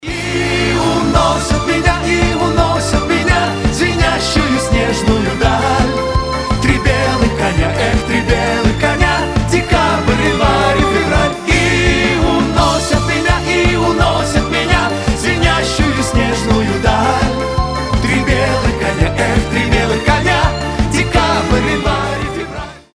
• Качество: 320, Stereo
мужской вокал
Cover
эстрадные
русская эстрада